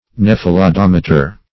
Search Result for " nephelodometer" : The Collaborative International Dictionary of English v.0.48: Nephelodometer \Neph`e*lo*dom"e*ter\ (n[e^]f`[-e]*l[-o]*d[o^]m"[-e]*t[~e]r), n. [Gr. nefe`lh a cloud + "odo`s way + -meter.]
nephelodometer.mp3